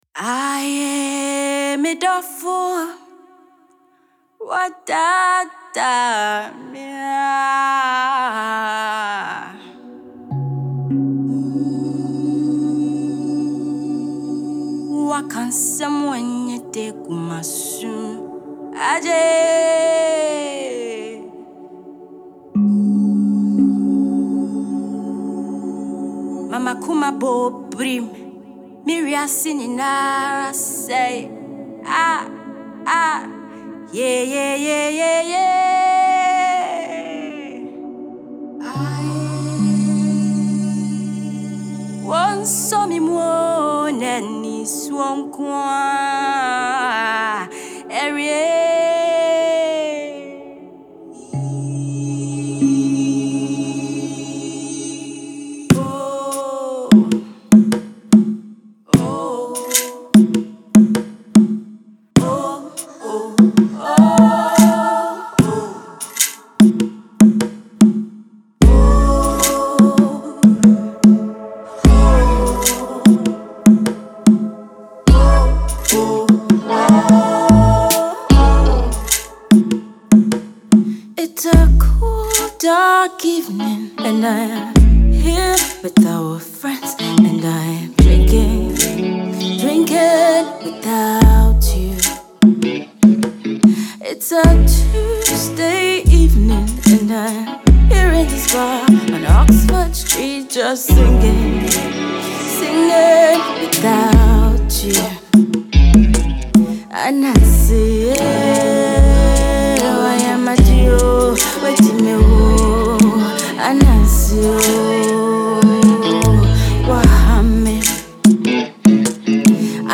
studio tune